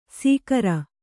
♪ sīkara